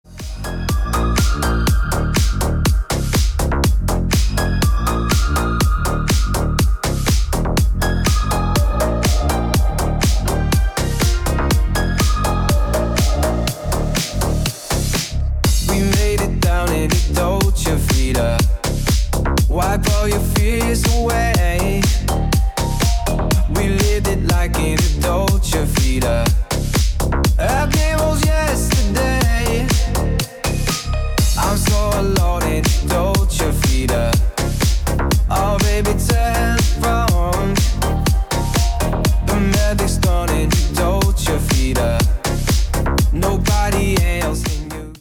Веселые мелодии